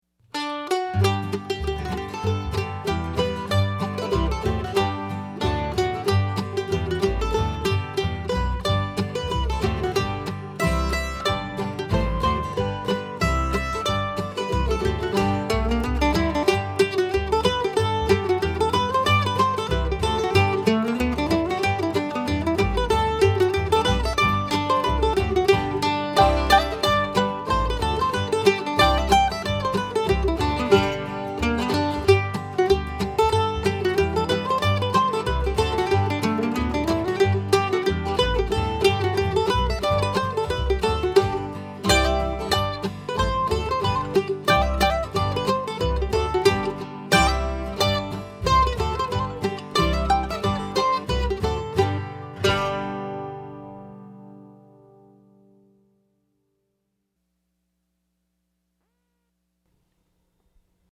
DIGITAL SHEET MUSIC - MANDOLIN SOLO
• Christmas, Bluegrass,Mandolin Solo